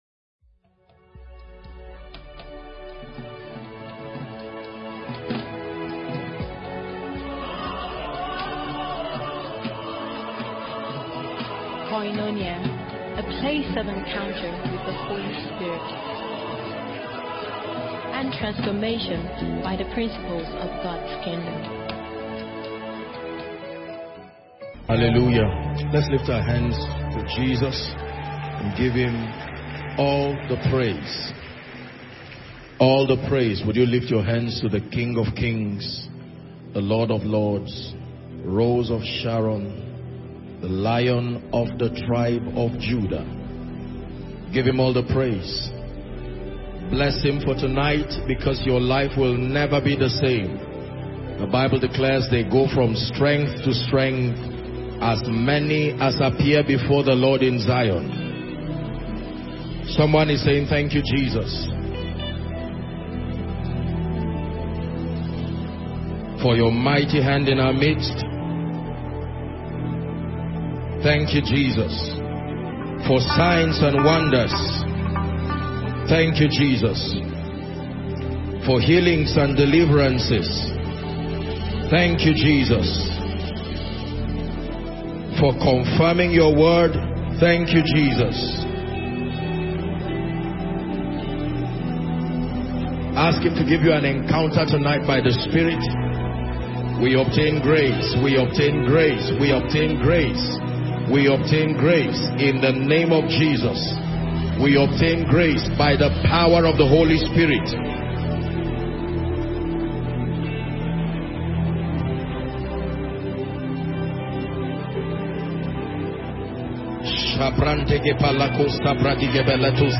SERMON EXCERPT Decisions (not location, time, gender, age) decide Destiny. Your decisions, more than your conditions will determine the quality of your life eventually.